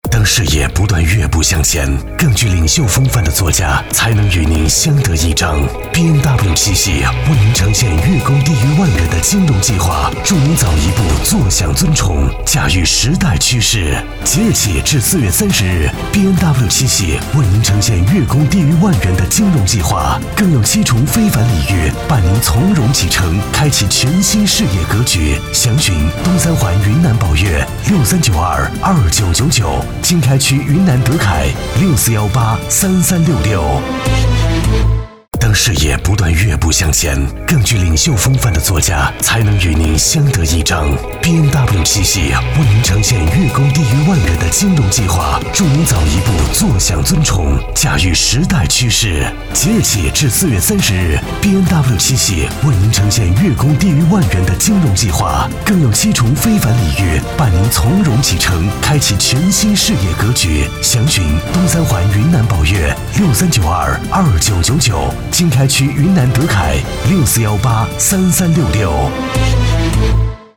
职业配音员全职配音员浑厚
• 男S347 国语 男声 宣传片-BMW-汽车宣传片-科技品质大气 大气浑厚磁性|沉稳